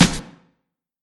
Snare (Whats Good).wav